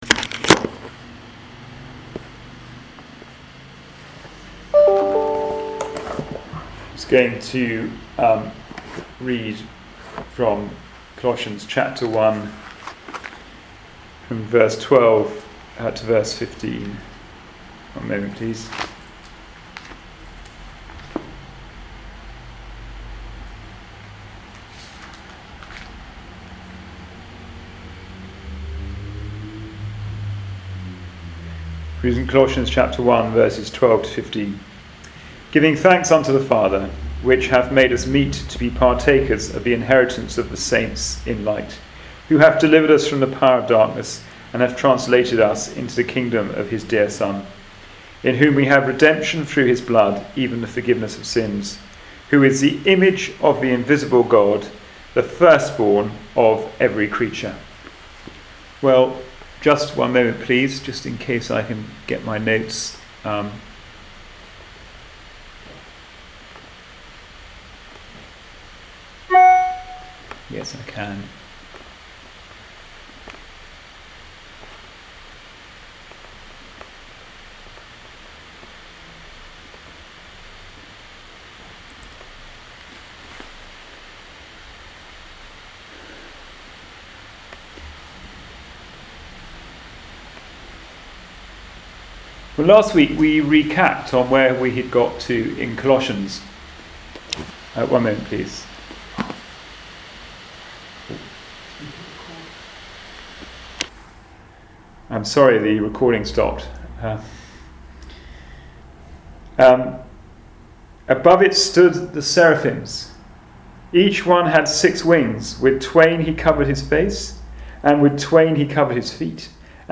Passage: Colossians 1:15 Service Type: Wednesday Bible Study